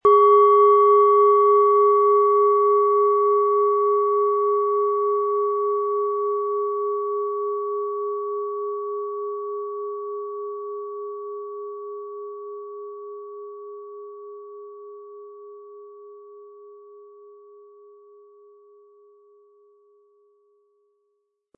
Wie klingt diese Planetenschale® Uranus?
PlanetentonUranus
SchalenformBihar
MaterialBronze